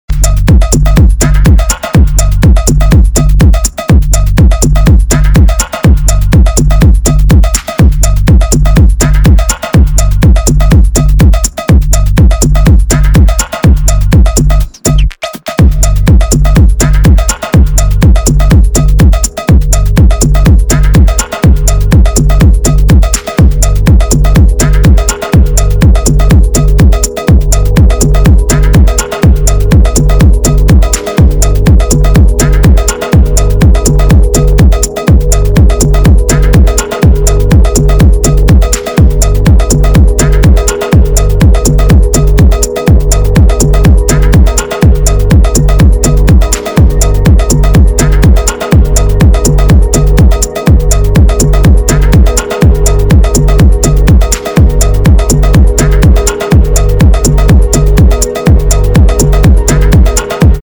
• Качество: 320, Stereo
ритмичные
Electronic
без слов
басы
цикличные